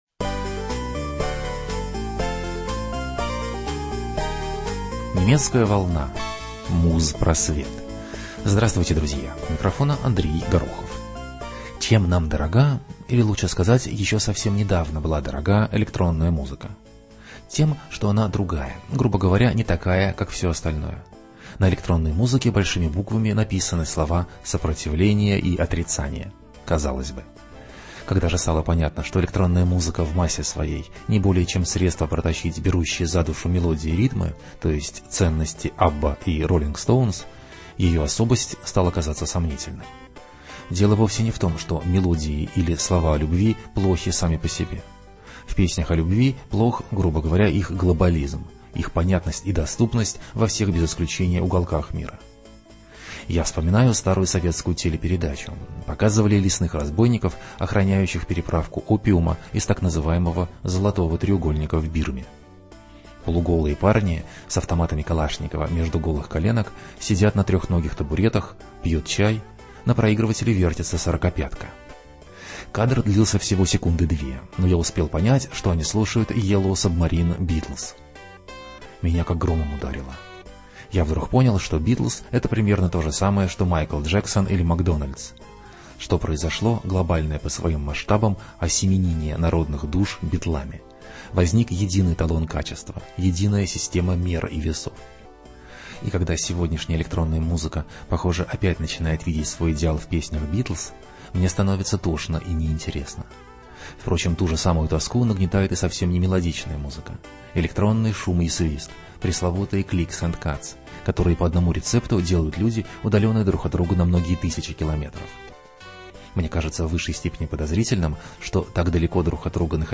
Проект 386dx - исполнение древних песен с помощью древнего компьютера.